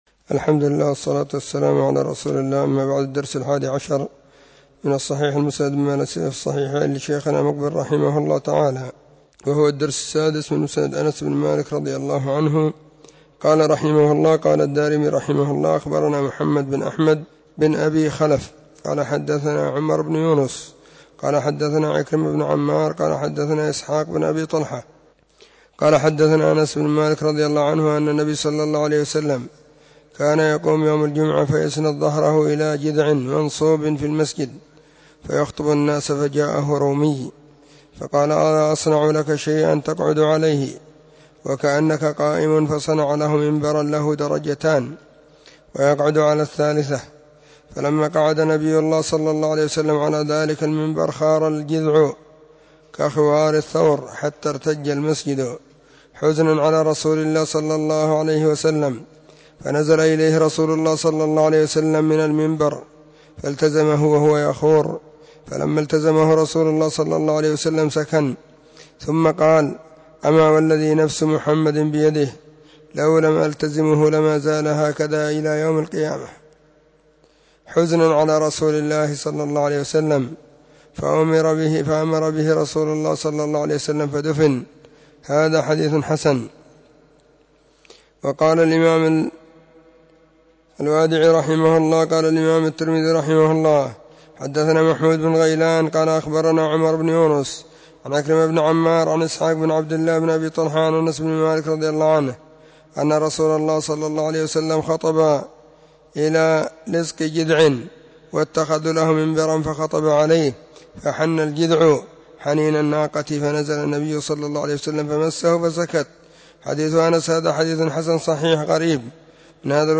خميس -} 📢مسجد الصحابة – بالغيضة – المهرة، اليمن حرسها الله.